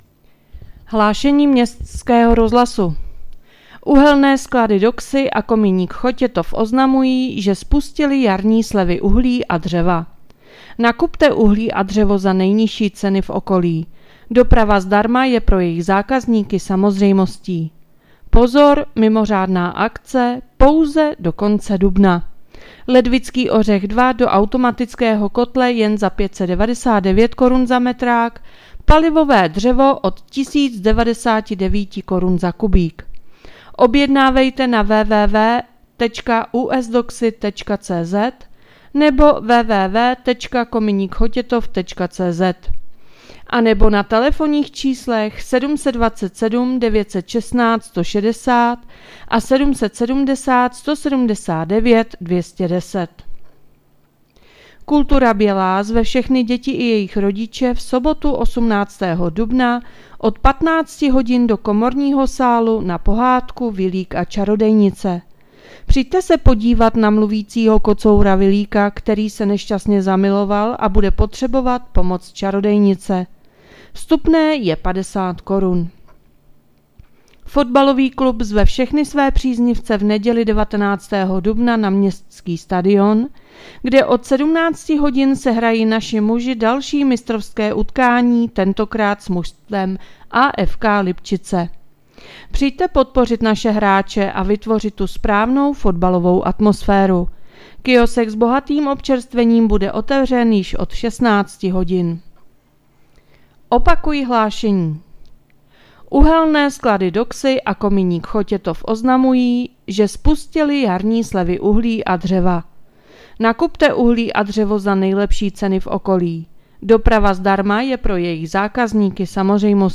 Hlášení městského rozhlasu 17.4.2026